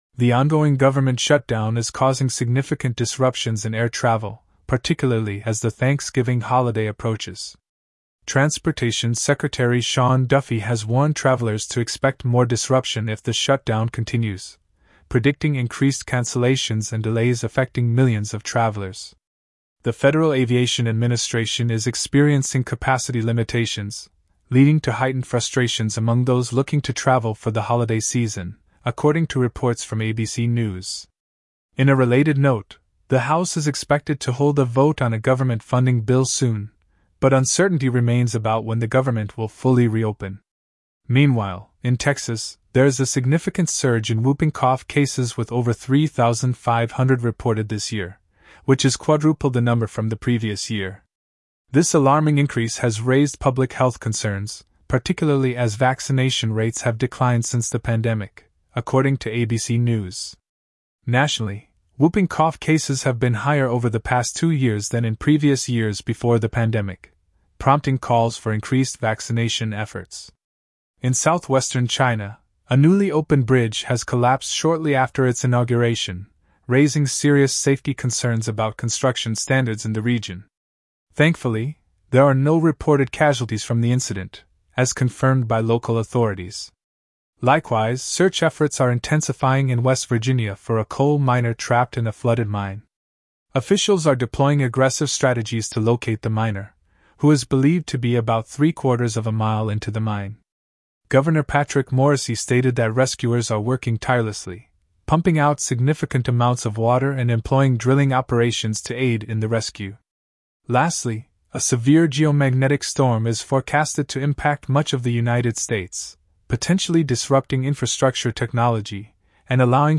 Top News Summary